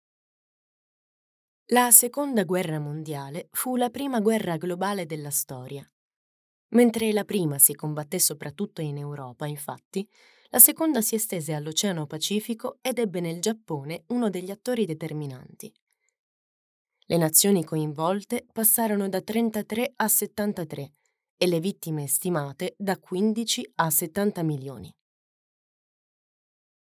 Attrice e speaker italiana. Una voce calda e avvolgente.
Sprechprobe: eLearning (Muttersprache):
A deep and enclosing voice for your needs.